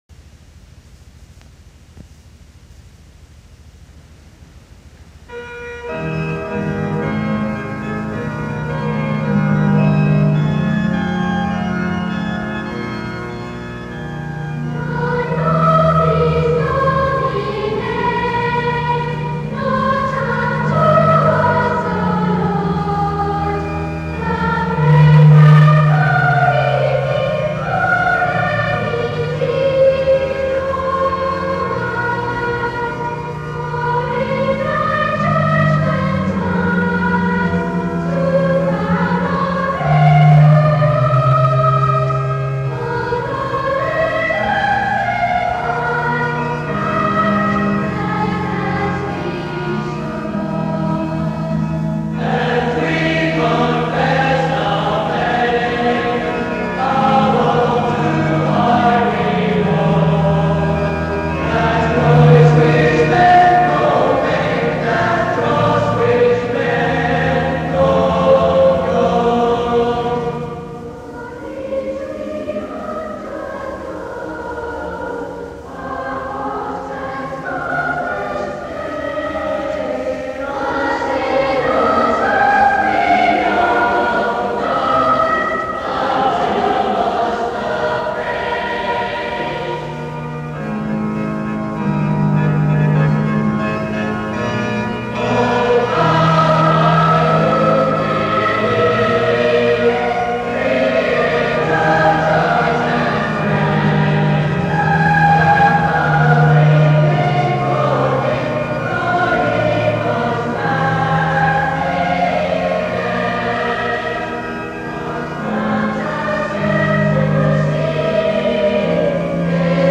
Side A, digitized from cassette tape:
Non Nobis Domine, by R. Kipling (words) and Roger Quilter, composed for the pageant of Parliament in 1936 (senior mixed choir).